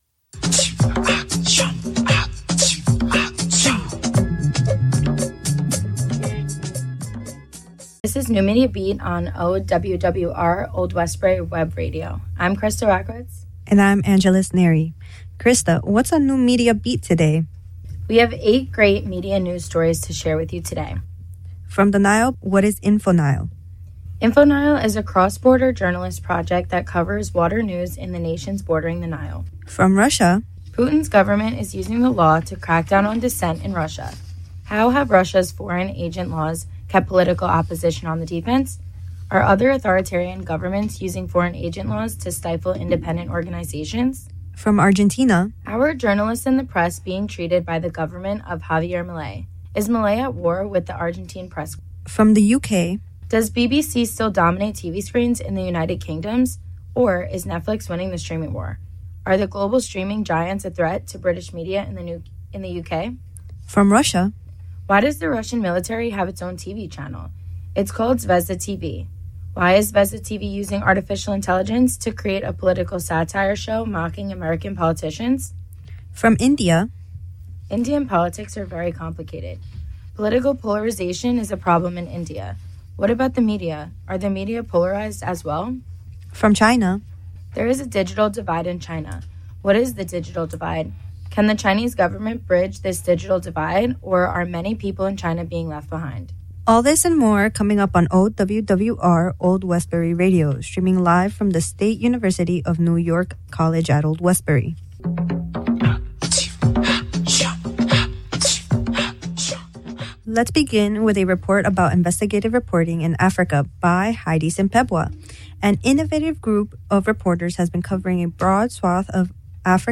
New Media Beat covers media news from around the world. NMB is a production of Media Studies students from SUNY @ Old Westbury.